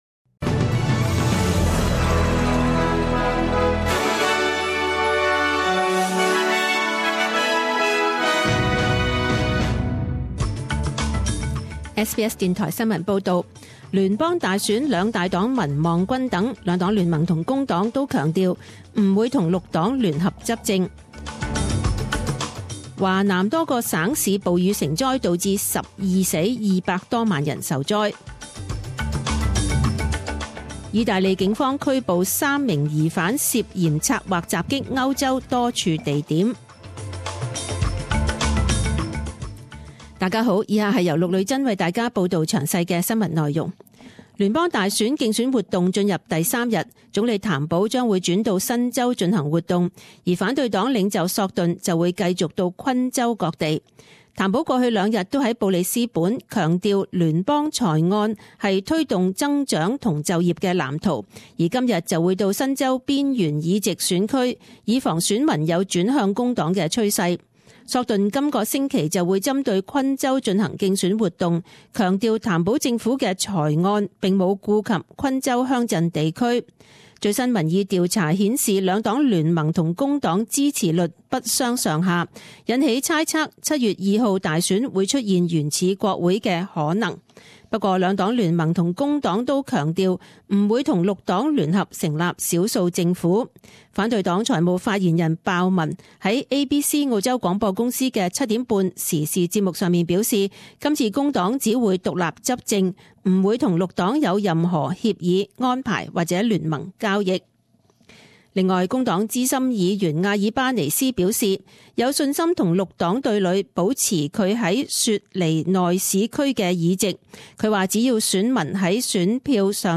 10am News Bulletin 11.05.2016
Details News Bulletins